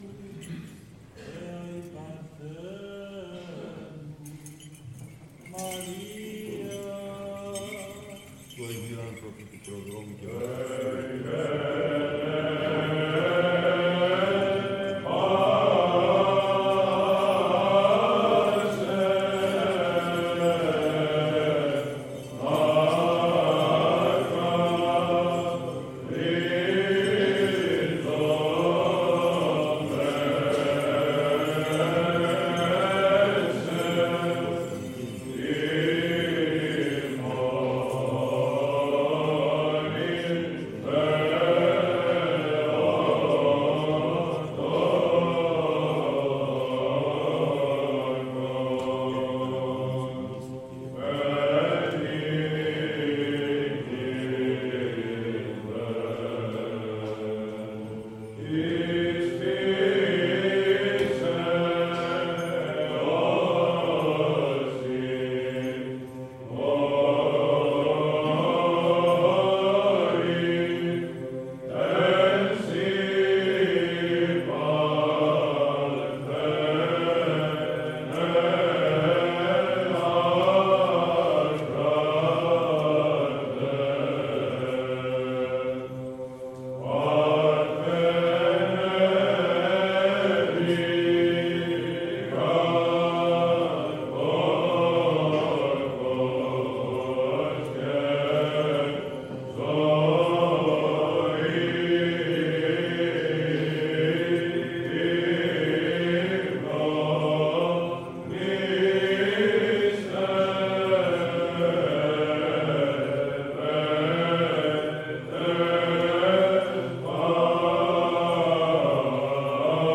Πρόκειται για μέλος Ματθαίου Βατοπαιδινού, του μεγάλου μαΐστορα του 19ου αι, σε ήχο πρώτο και ύφος πανηγυρικό και ευχάριστο όπως αρμόζει στη μνήμη της Μεταστάσεως της Παναγίας Θεοτόκου.